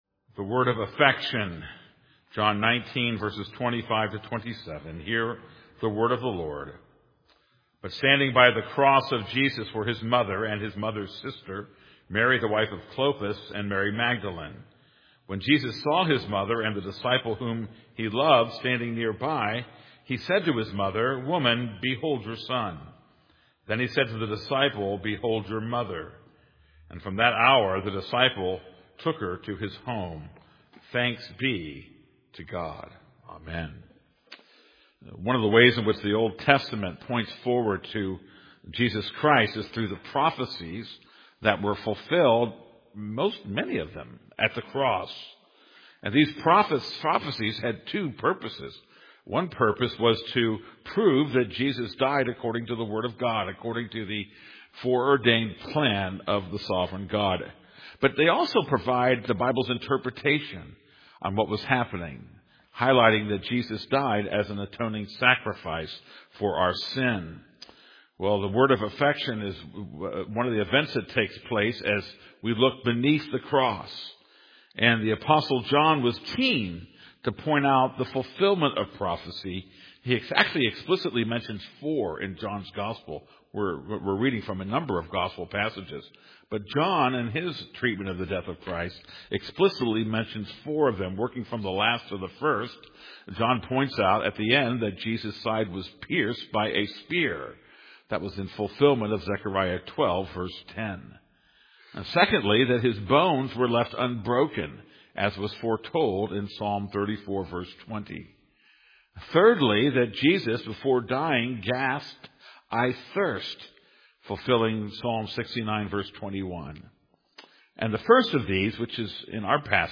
This is a sermon on John 19:25-27.